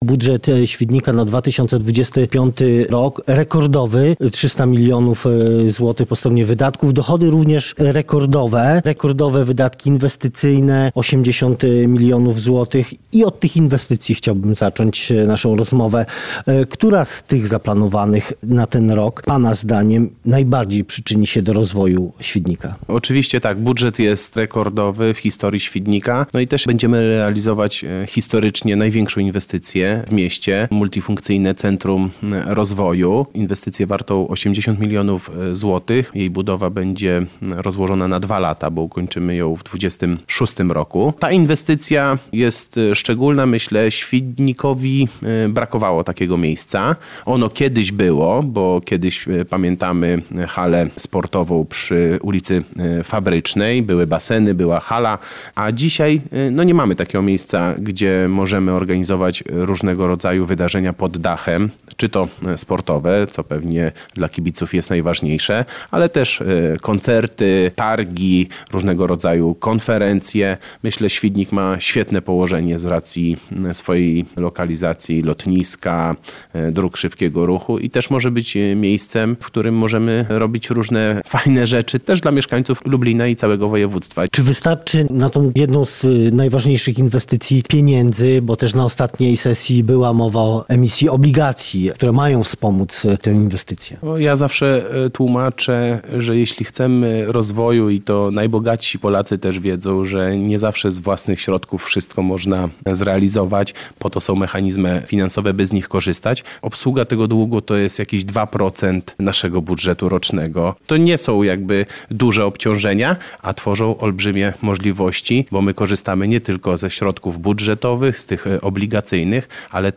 Rozmowa z burmistrzem Świdnika Marcinem Dmowskim